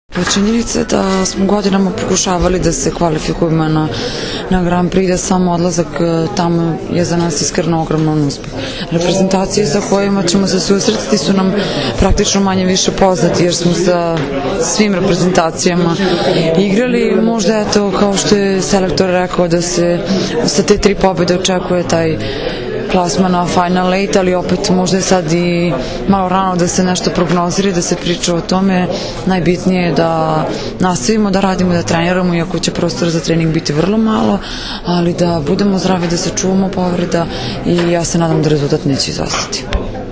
Tim povodom danas je u u beogradskom hotelu „M“ održana konferencija za novinare